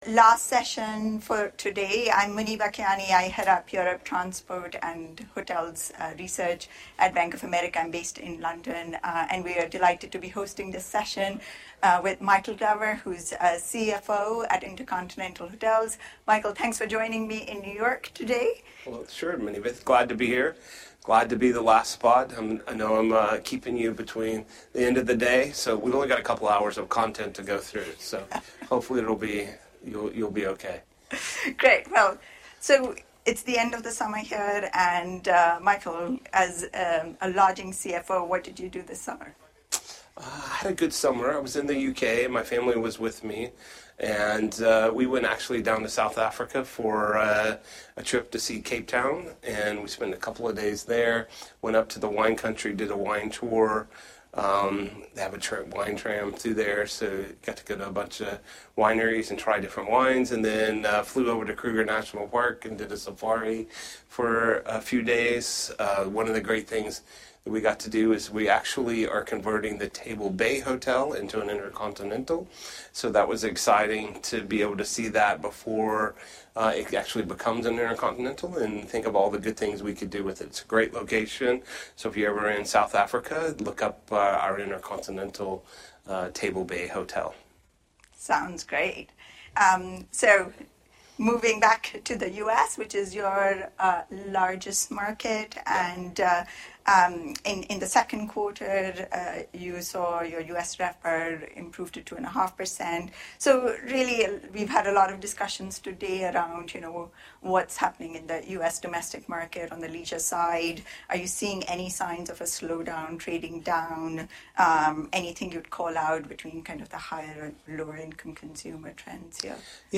Bank of America fireside chat
at the ‘Bank of America 2024 Gaming & Lodging Conference’ in New York.